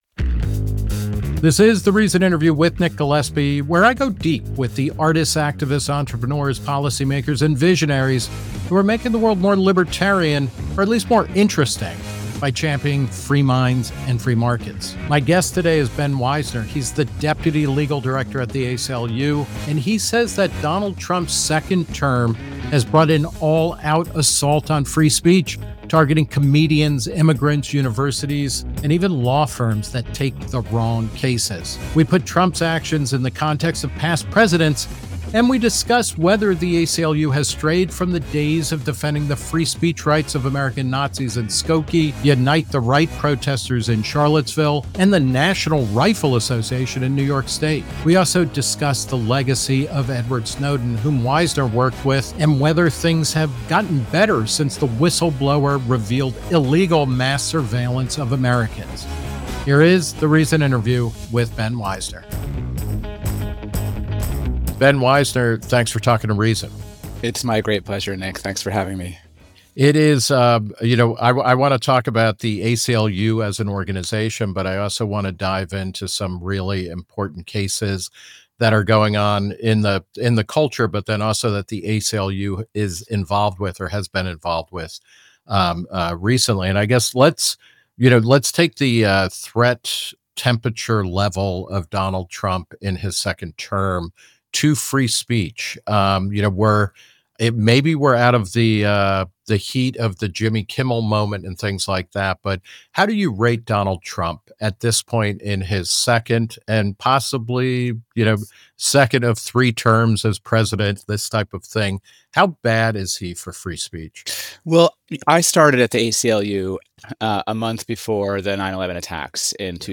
The Reason Interview With Nick Gillespie